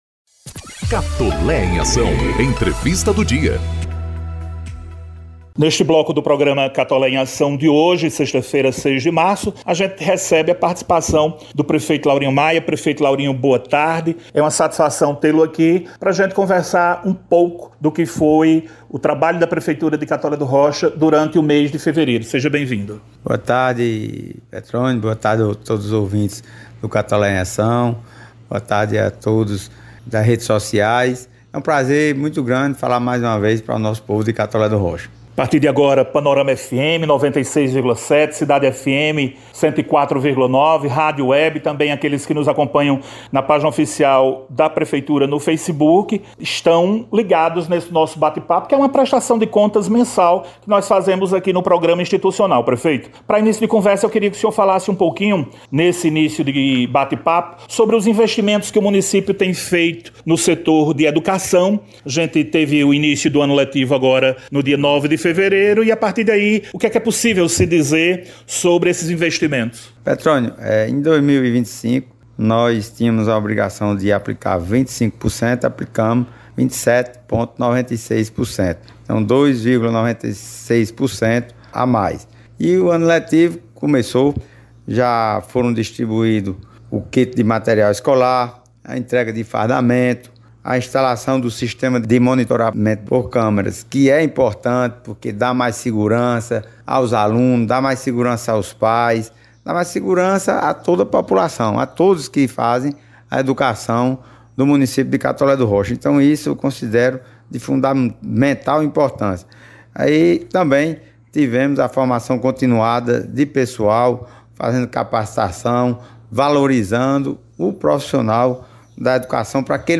Na sexta-feira (06), o prefeito de Catolé do Rocha, Laurinho Maia, participou do programa Catolé em Ação, gerado pela Rádio Panorama FM 96,7 e retransmitido pela Rádio Cidade FM 104,9,…